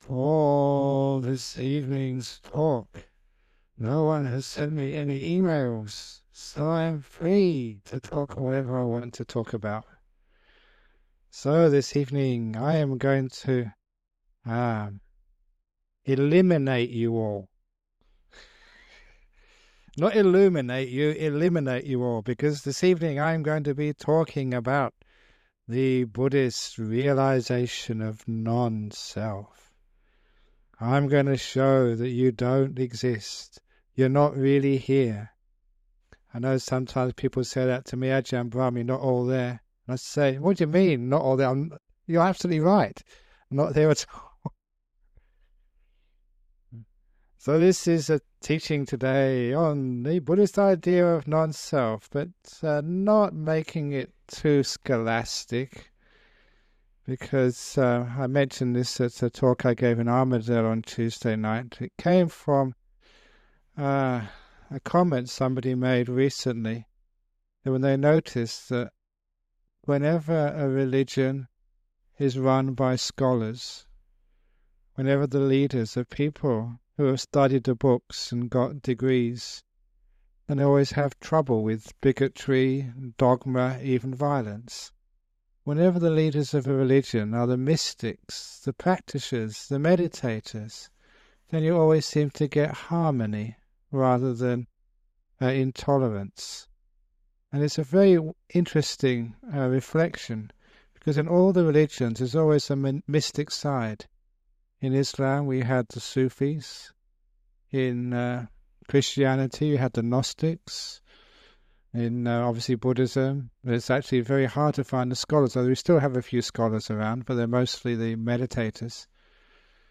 Remastered classic teachings of the greatest meditation master in the modern Western world - Ajahn Brahm!